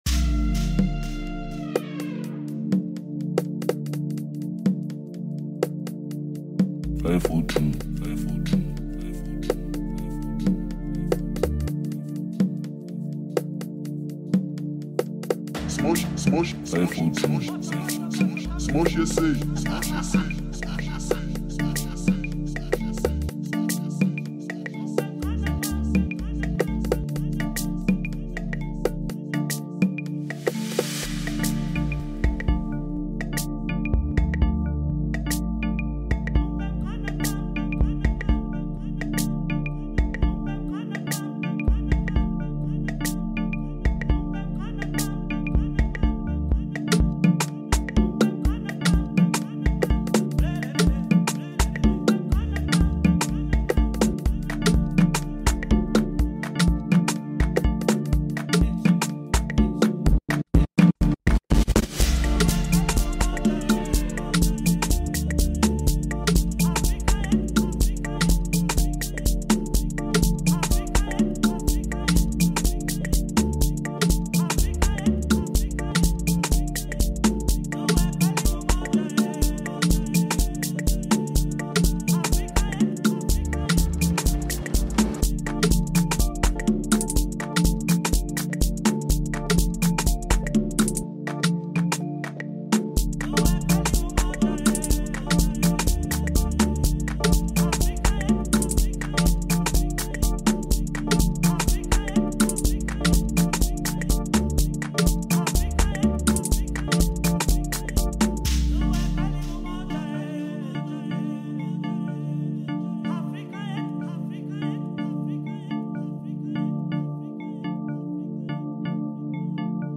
Home » Hip Hop » Amapiano » DJ Mix